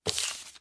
PaperGrab03.wav